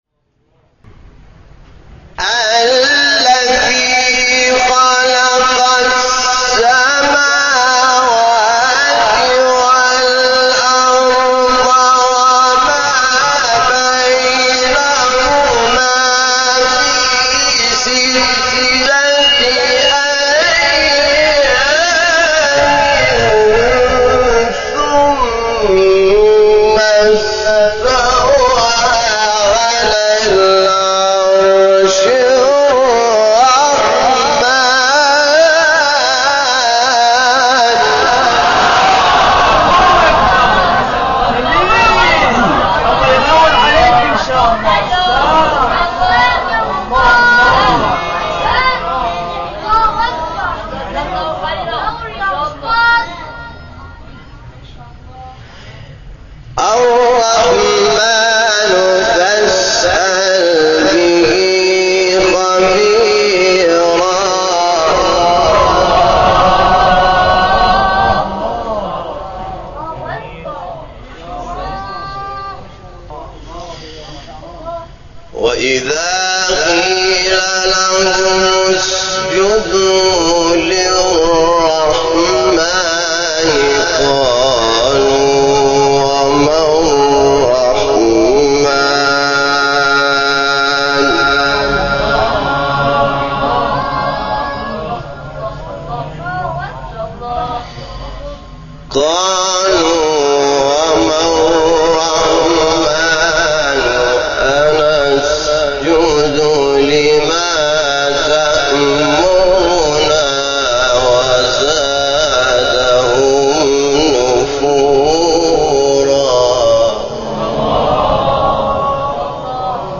سوره : فرقان آیه : 59-63 استاد : حامد شاکرنژاد مقام : مرکب خوانی( رست * حجاز) قبلی بعدی